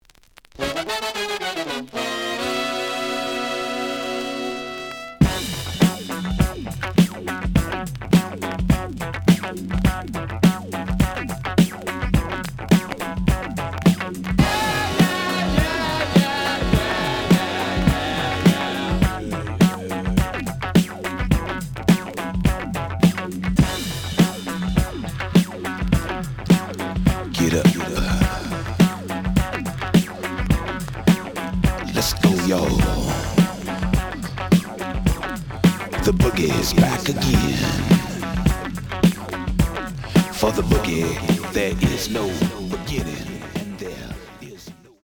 The audio sample is recorded from the actual item.
●Genre: Funk, 70's Funk
Some click noise on later half of A side due to scratches.